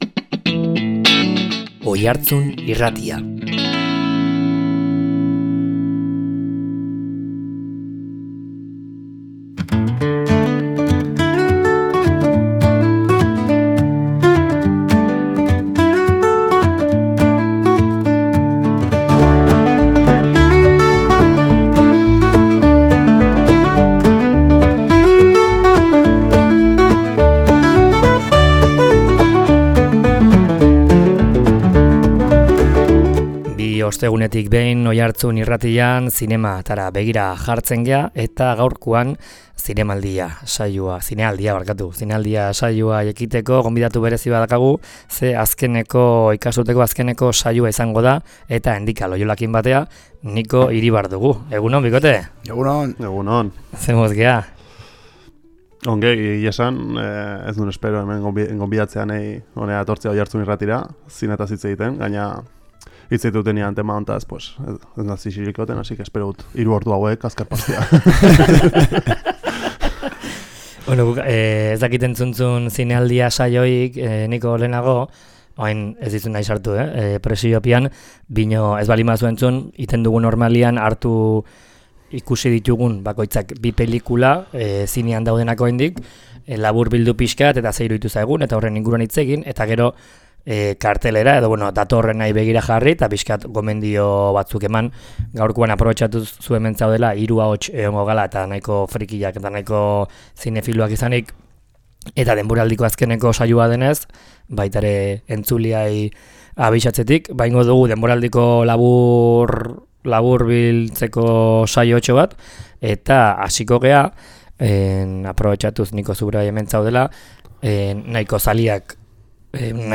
Denboraldiko azken irratsaioa izan dugu gaurkoan Zinealdian.